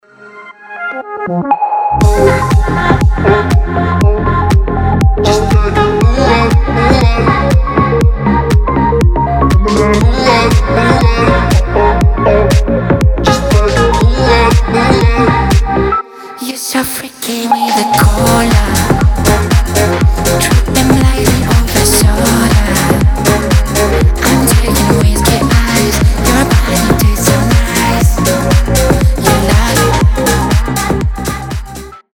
мелодичные
космические
house